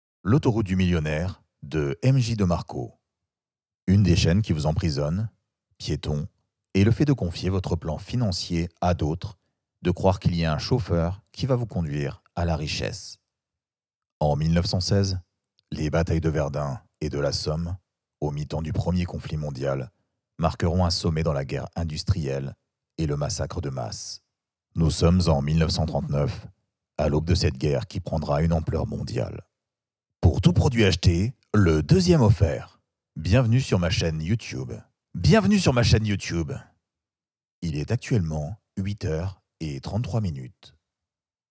Voix off
démo
voix-grave